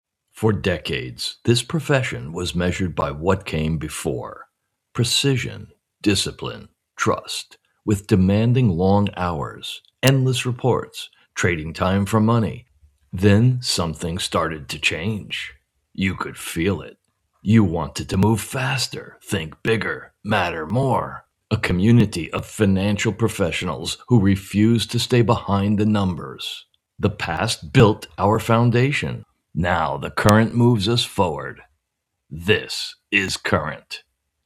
Explainer & Whiteboard Video Voice Overs
Adult (30-50)